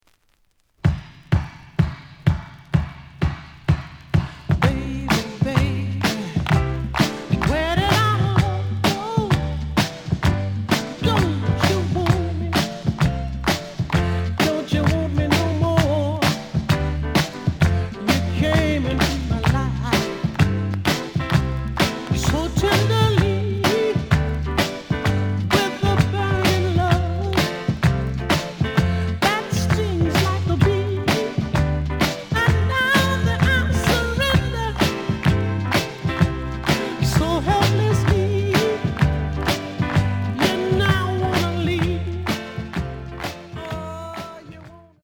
The audio sample is recorded from the actual item.
●Genre: Soul, 70's Soul
Slight edge warp. But doesn't affect playing.